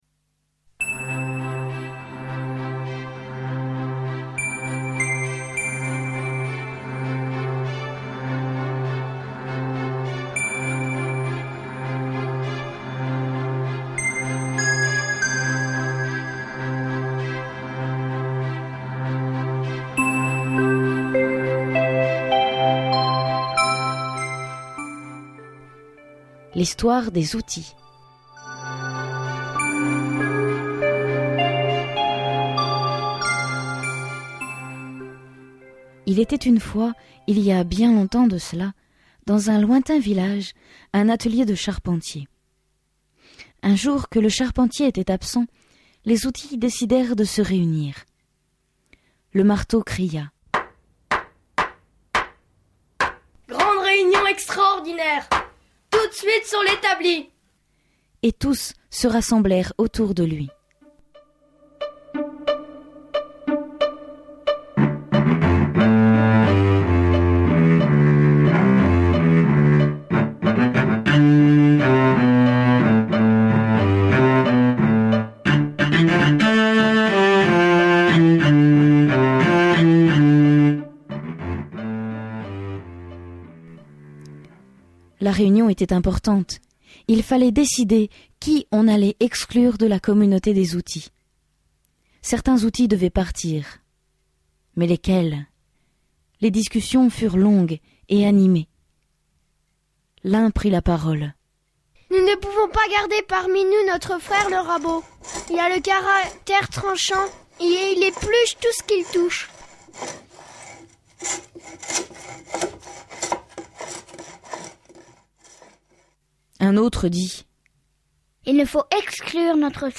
4 contes de Noël pour enfants.